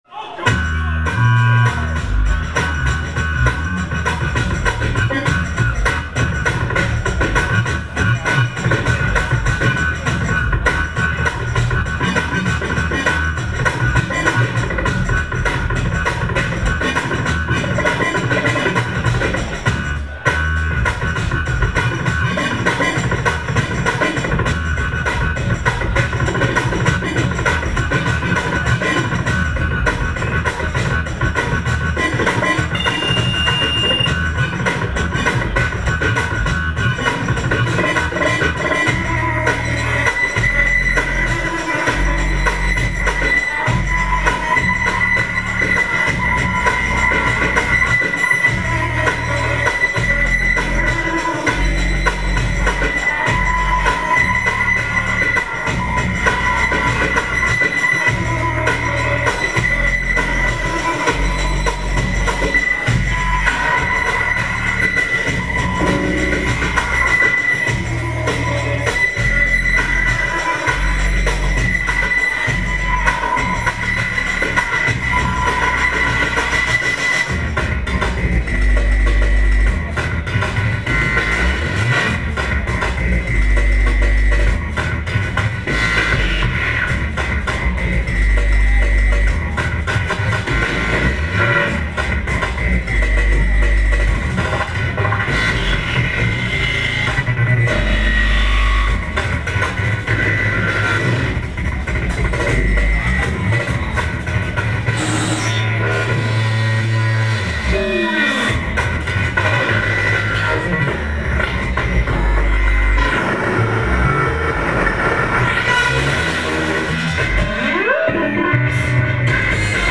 location San Francisco, USA